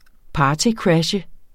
Udtale [ ˈpɑːtiˌkɹaɕə ]